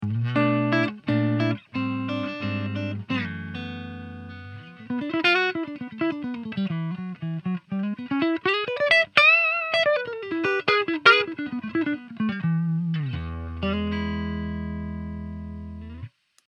Country riff 2